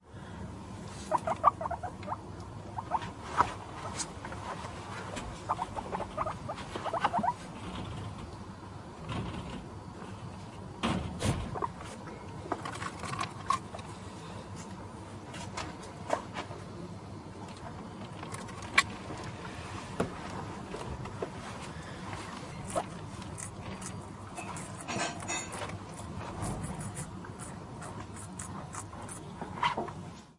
几内亚猪
描述：一只豚鼠发出吱吱声，吃草。
标签： 豚鼠 squeek
声道立体声